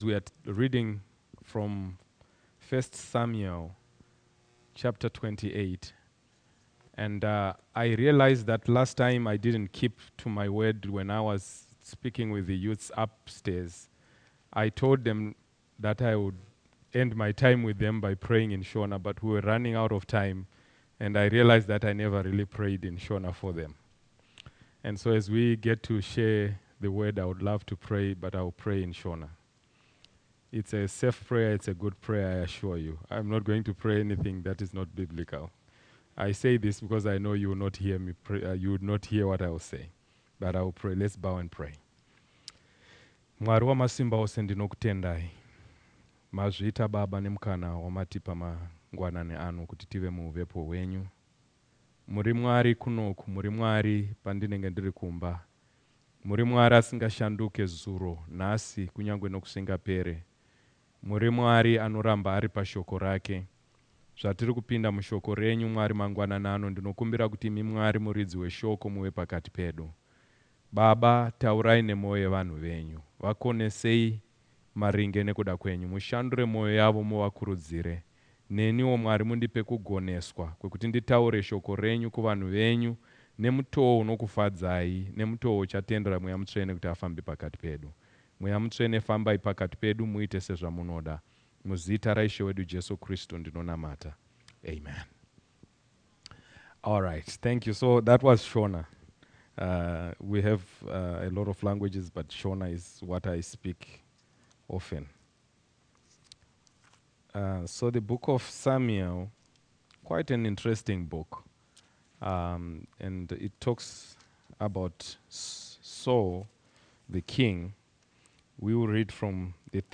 Missionary Preacher